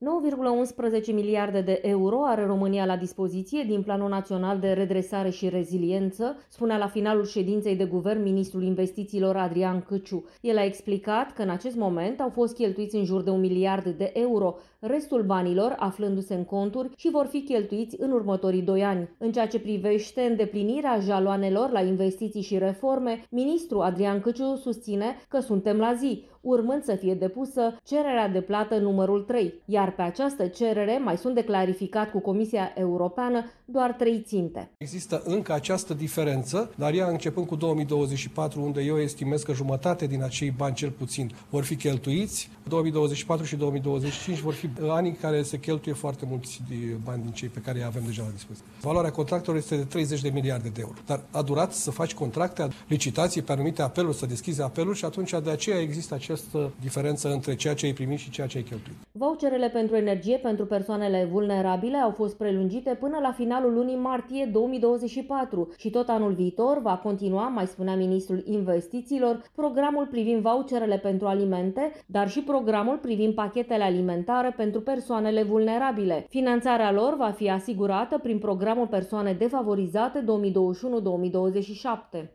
Ministrul Mediului, Mircea Fechet, a precizat astăzi, la Radio România Actualităţi că, numai ambalajele inscripţionate cu sigla sistemului de garanţie-returnare vor putea fi predate la automatele de colectare din magazine: